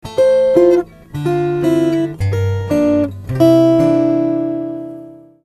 わざわざルートのF音とメジャー7thのE音を半音でぶつけてます。